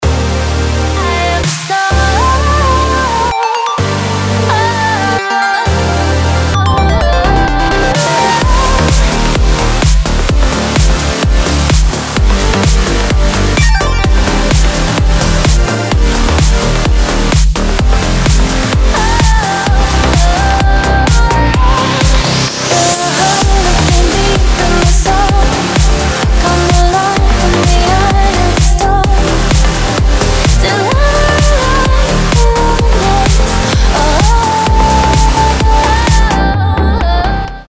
• Качество: 192, Stereo
Новый Trance ремикс с прекрасным вокалом